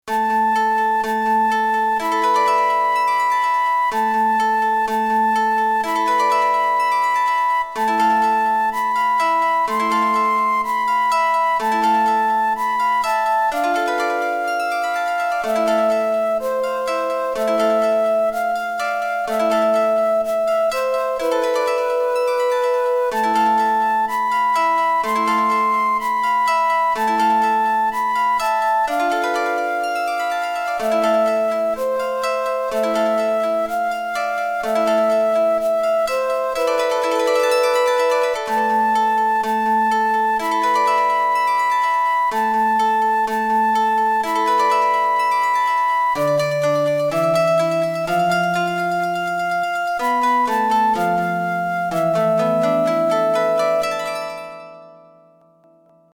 日本古謡
以前SH-51で作成したmmfファイルをmp3ファイルに録音し直しました。
再生する機種により、音は、多少異なって聞こえます。